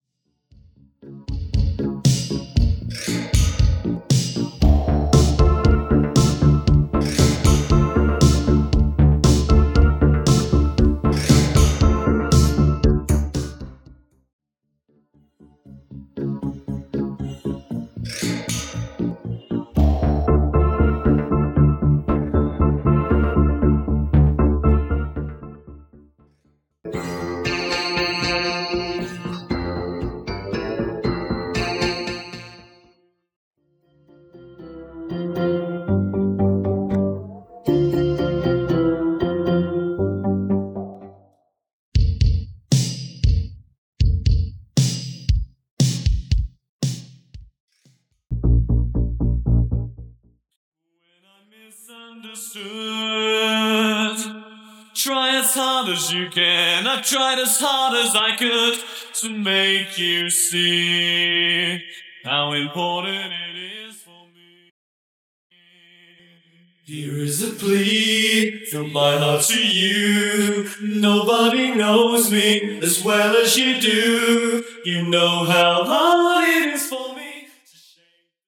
Bassline Synths & 808 Line stem
Percussion & Drums Stem
Studio Instrumental
Synths , Strings, Pads, Keys & FX Stem